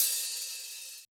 Index of /musicradar/Kurzweil Kit 04
CYCdh_Kurz04-OpHat.wav